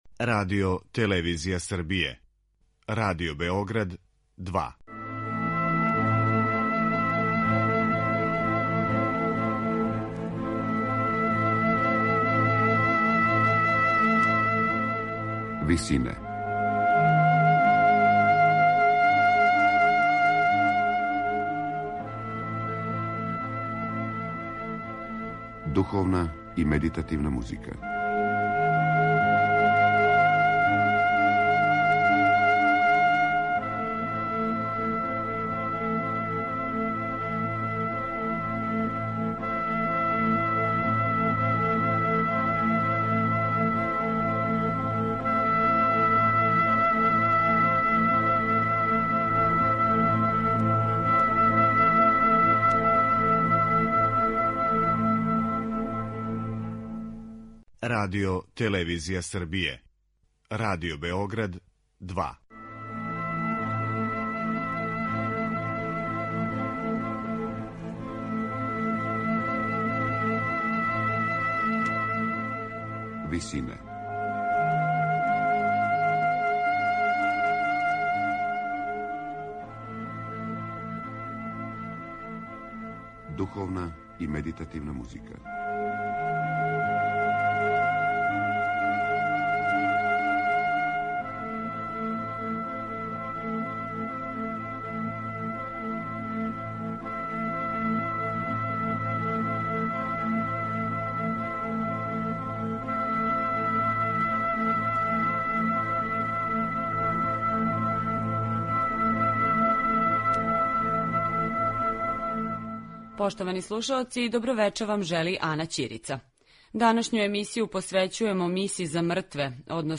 Међу бројним духовним остварењима Жана Жила, француског барокног композитора, Реквијем (1704/1705) за солисте, мешовити хор и оркестар jе у једном периоду био међу најцењенијим композицијама у Француској.